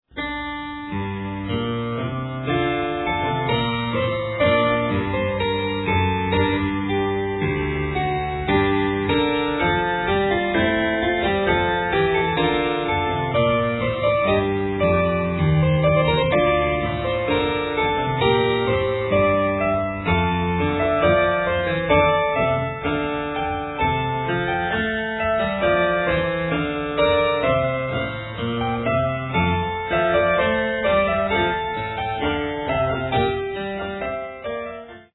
Early stereo master tapes, recorded at 30 ips